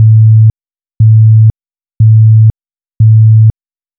Sirena electrónica
Tono 04 - Intermitente 110Hz.
Tono 04 - Intermitente 110Hz..wav